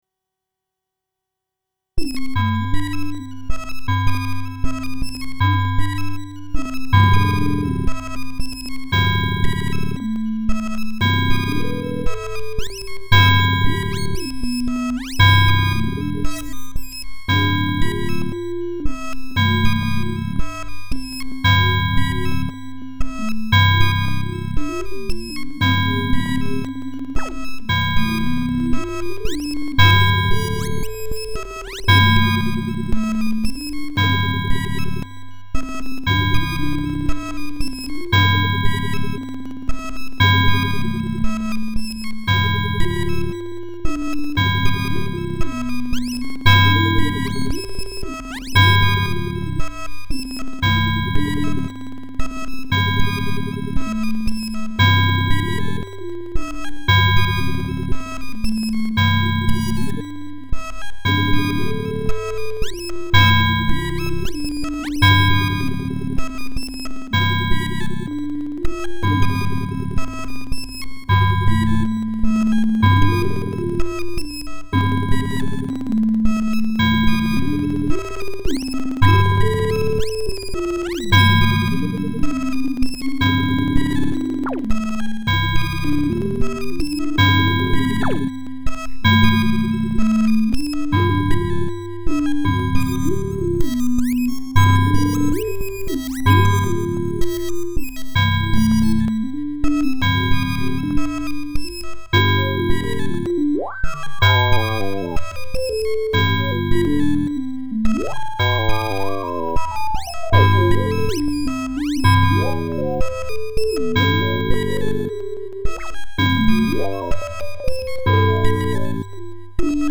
Tonight's theme is "Energetic."
self-generative modular performance